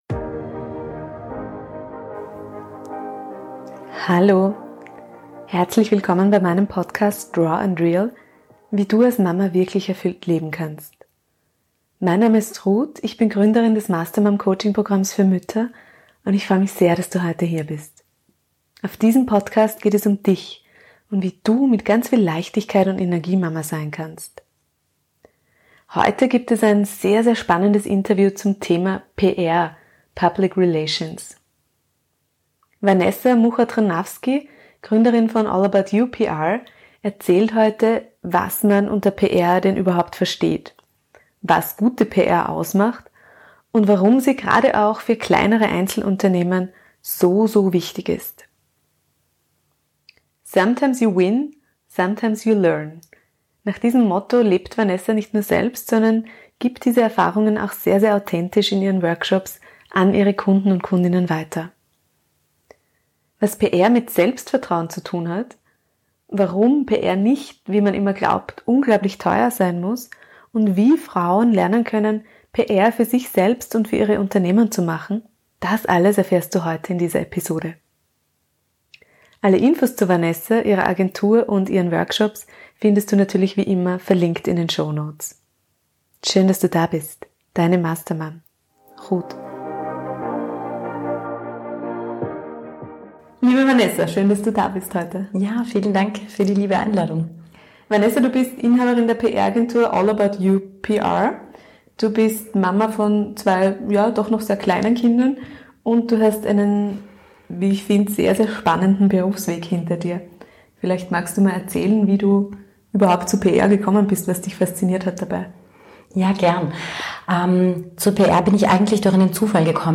Heute gibt es ein sehr spannendes Interview zum Thema Public Relations.